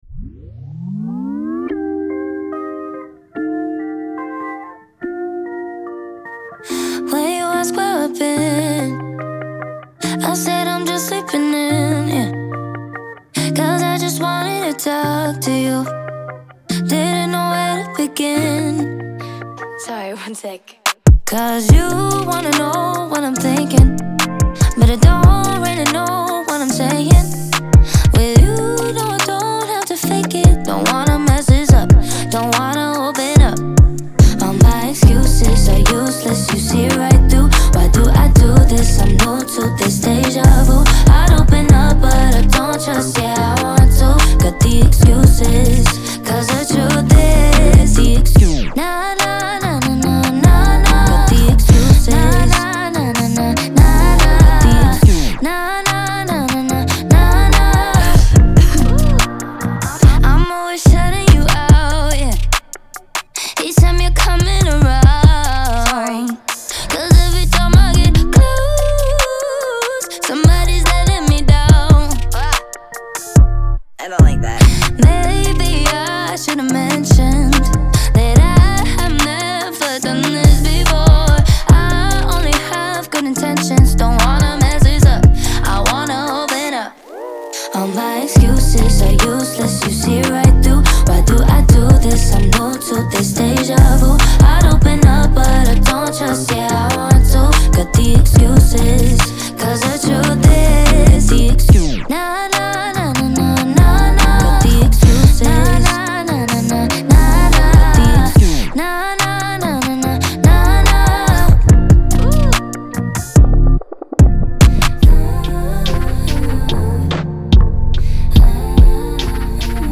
современная поп-песня